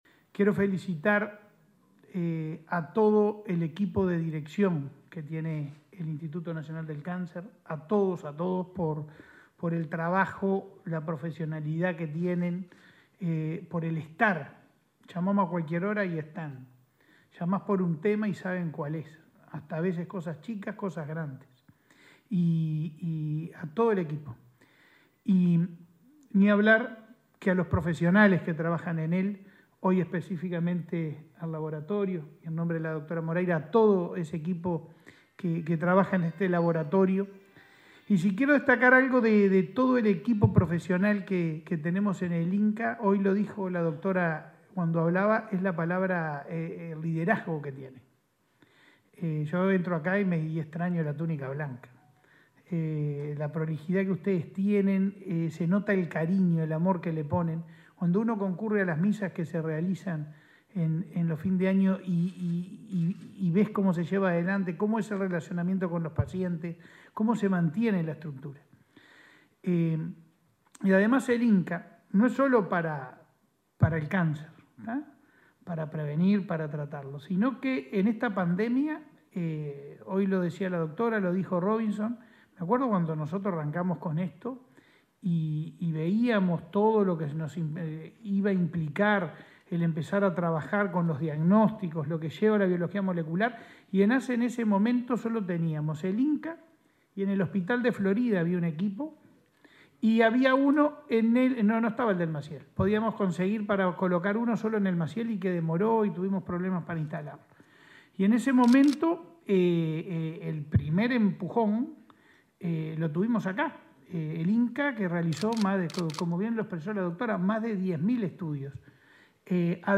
Palabras del presidente de ASSE, Leonardo Cipriani
Palabras del presidente de ASSE, Leonardo Cipriani 08/06/2022 Compartir Facebook X Copiar enlace WhatsApp LinkedIn El presidente de la Administración de los Servicios de Salud del Estado (ASSE), Leonardo Cipriani, participó en la inauguración de un laboratorio en el Instituto Nacional del Cáncer (INCA).